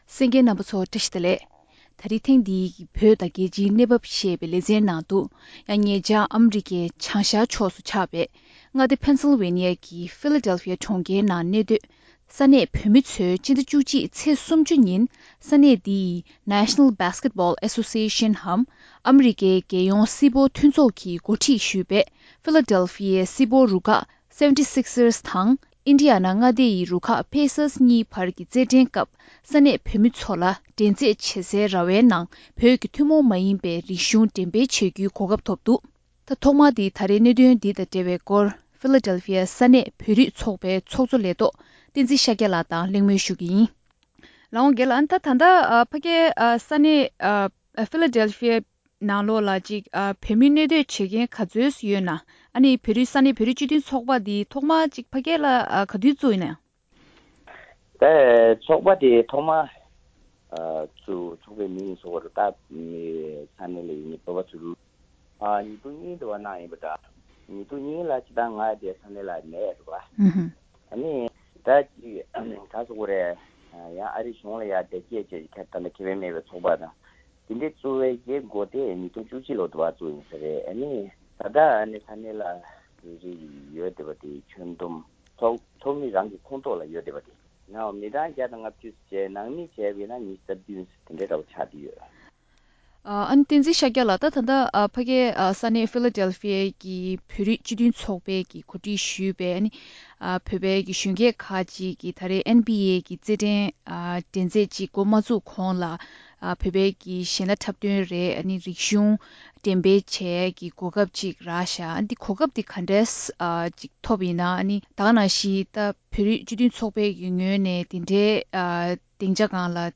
གླེང་མོལ་ཞུས་པར་གསན་རོགས།།